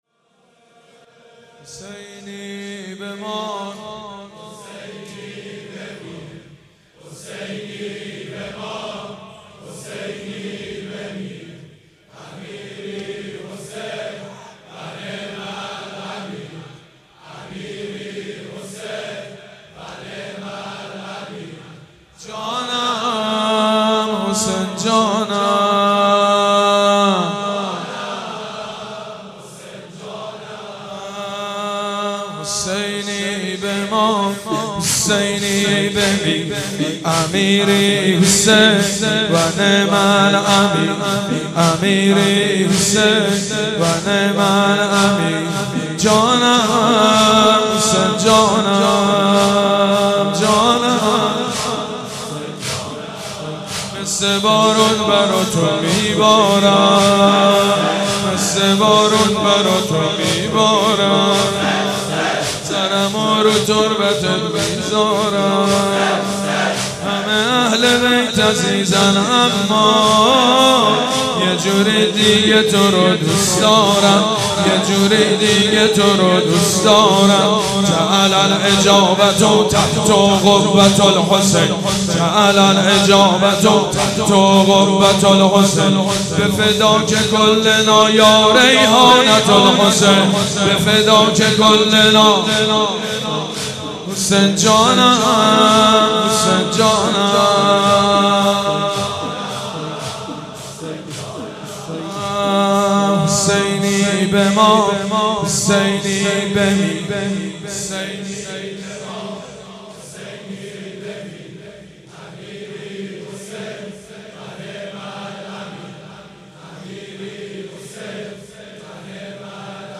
مجموعه مراسم بنی فاطمه در شب تاسوعای 93